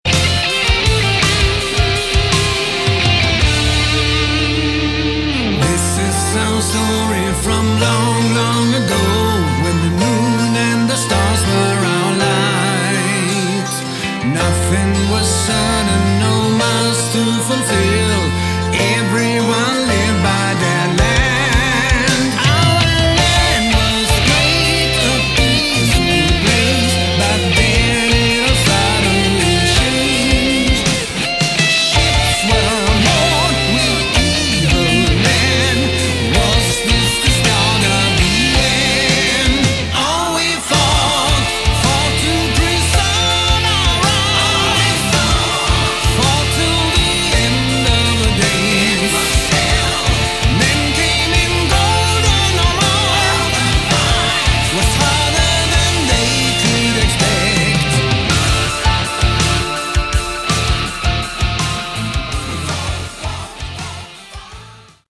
Category: AOR
drums, keyboards
lead guitars, rhythm guitars, bass
vocals